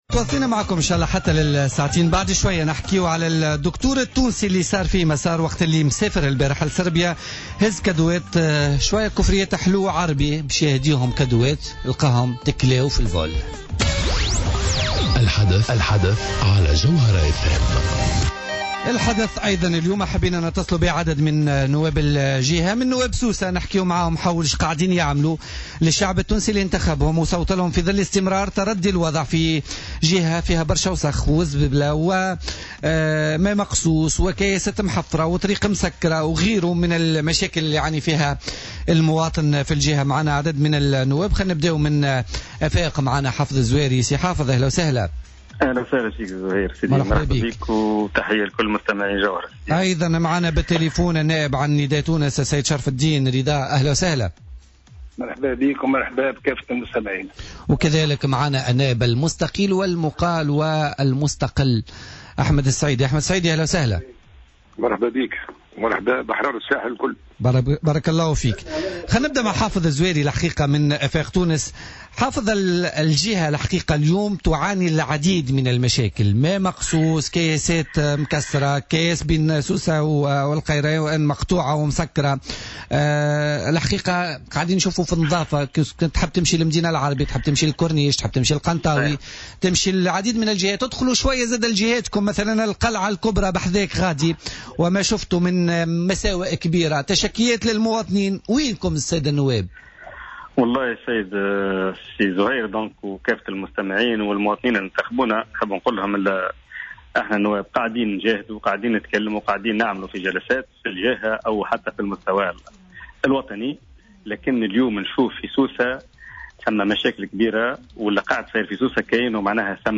أكد النائب عن افاق تونس حافظ الزواري في مداخلة له في برنامج الحدث اليوم الإثنين 8 اوت 2016 أن هناك حملة ممنهجة ضد سوسة وجهة الساحل ورغبة في تهميشها على حد قوله.